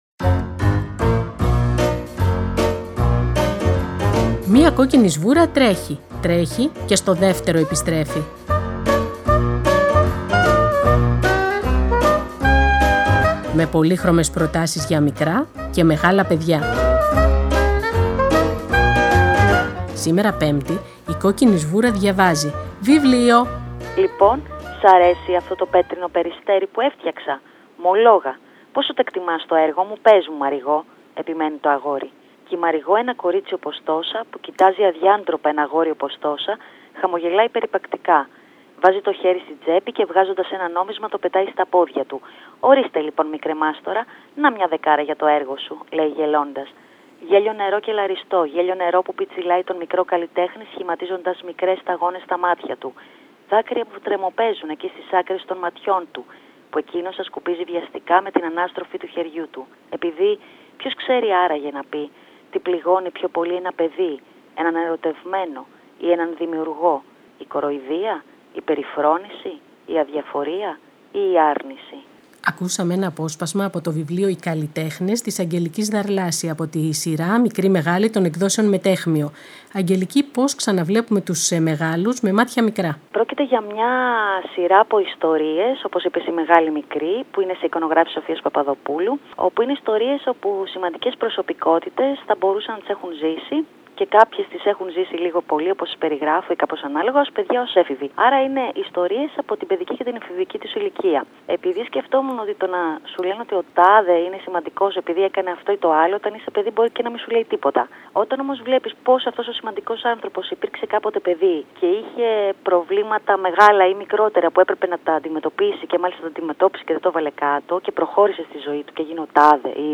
Σήμερα Πέμπτη, η Κόκκινη Σβούρα διαβάζει το βιβλίο “Οι Καλλιτέχνες” της Αγγελικής Δαρλάση από τη σειρά Μεγάλοι Μικροί των εκδόσεων Μεταίχμιο.